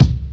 • Old School Good Hip-Hop Kick Drum Sample C Key 141.wav
Royality free bass drum sample tuned to the C note. Loudest frequency: 337Hz
old-school-good-hip-hop-kick-drum-sample-c-key-141-jXc.wav